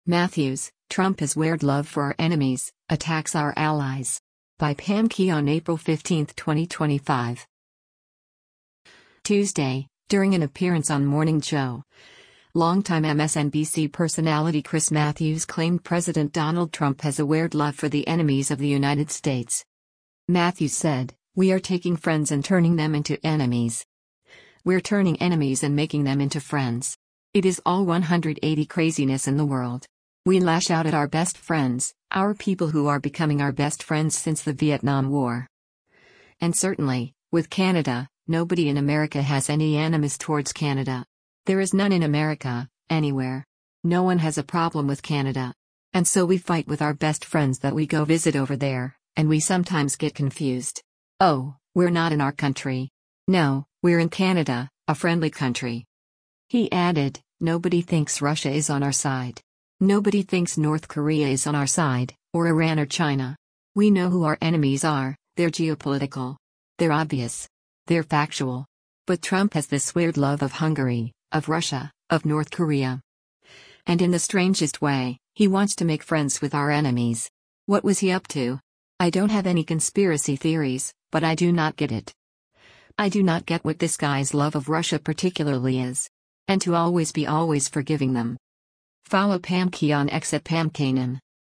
Tuesday, during an appearance on “Morning Joe,” long-time MSNBC personality Chris Matthews claimed President Donald Trump has a “weird love” for the enemies of the United States.